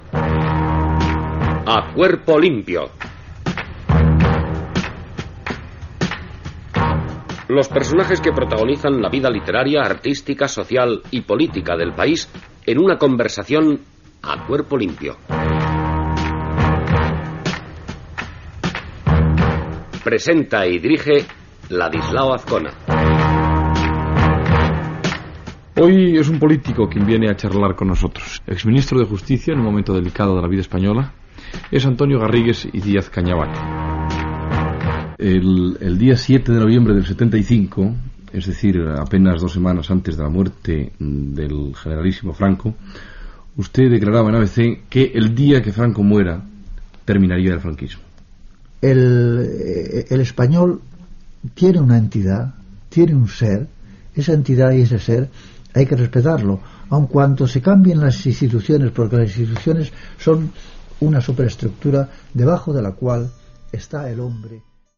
Careta i presentació d'Antonio Garrigues y Díaz-Cañabate, ministre de justícia espanyol.
Informatiu